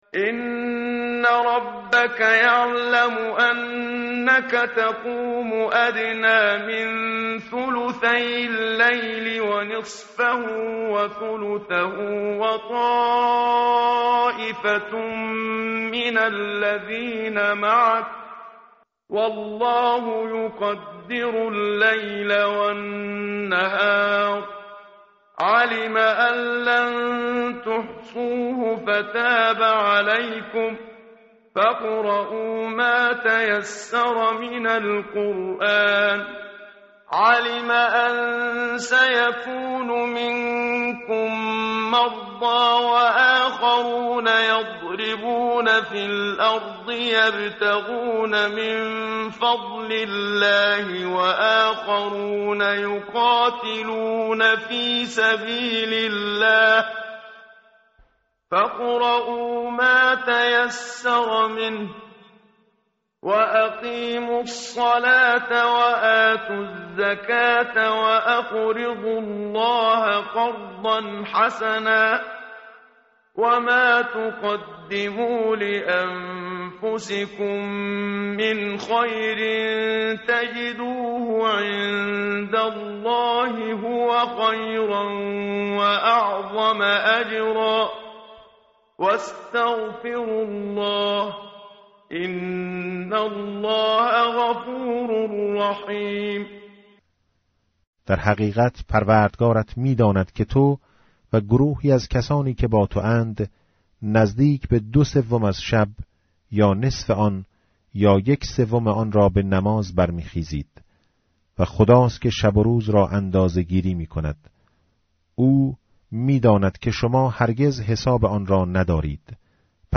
tartil_menshavi va tarjome_Page_575.mp3